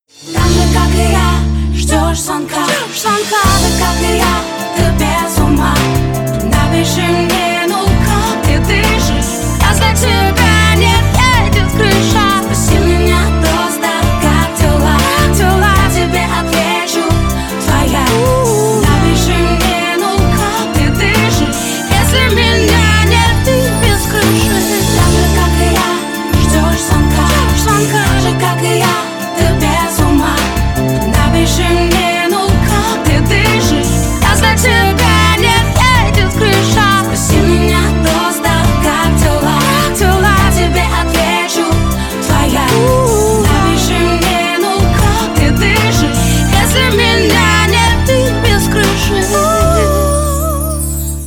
• Качество: 320, Stereo
романтичные
красивый женский голос
vocal